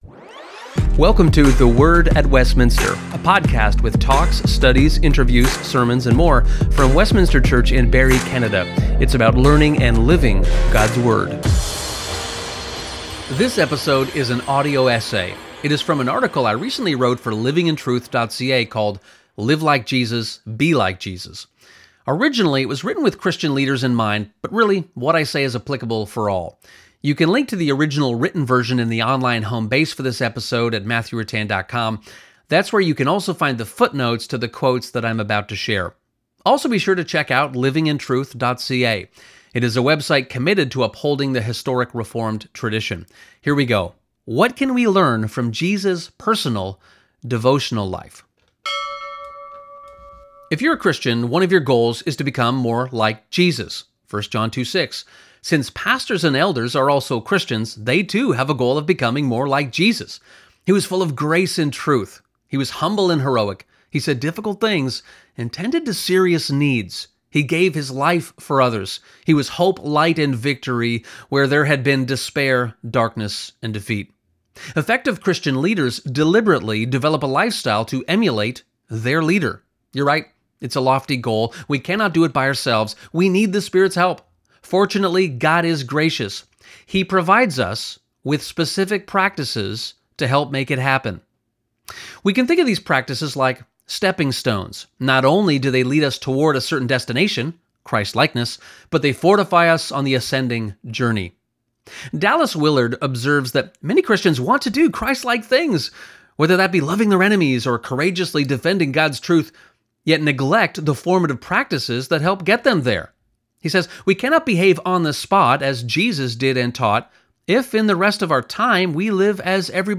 This episode is in the form of an audio essay.